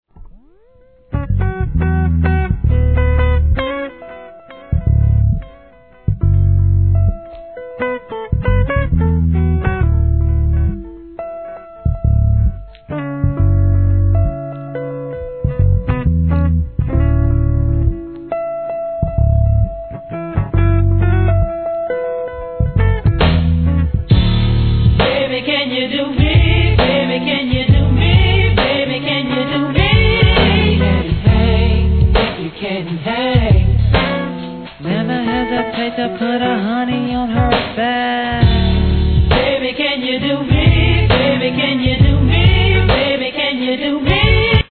G-RAP/WEST COAST/SOUTH
本当に実力があるコーラスワークはコアなR&Bファンの肥えた耳も納得させる出来栄え♪